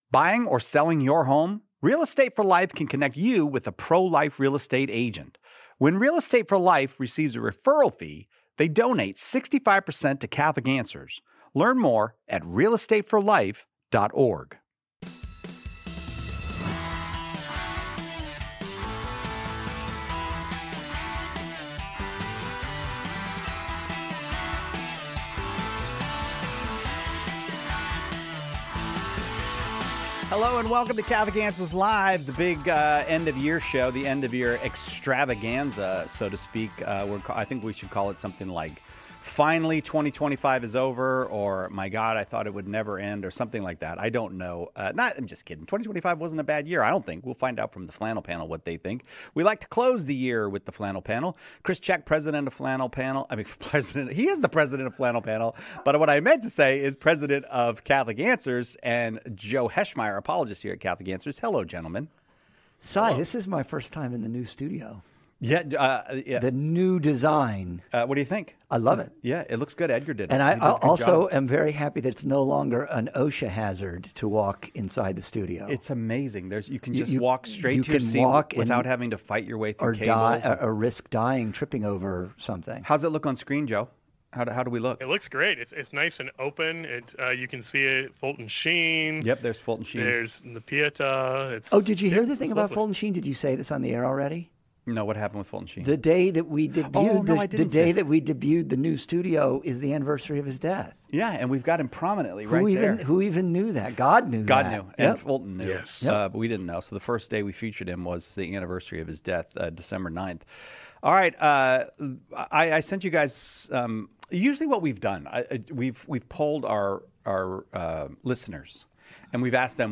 The Flannel Panel looks back on 2025 with a lively year-in-review, asking whether Catholics truly “won the internet,” examining the significance of Matt Fradd j...